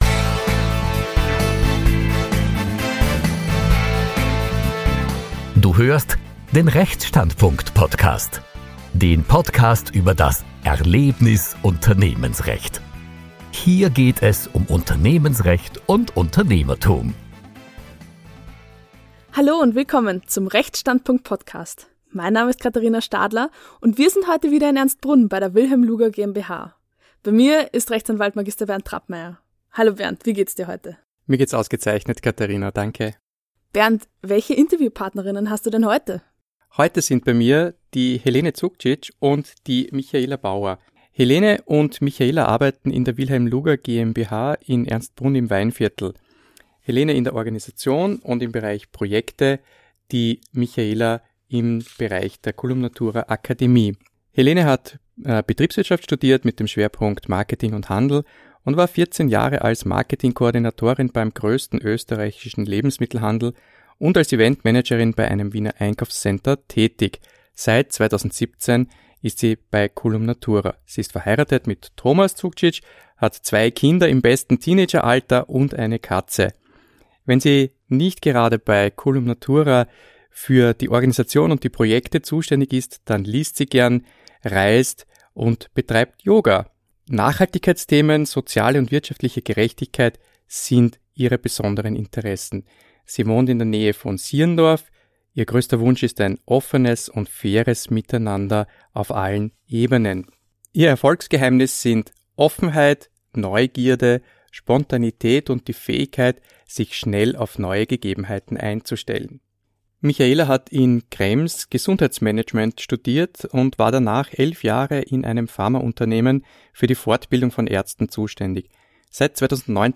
In dieser Folge sprechen wir im Interview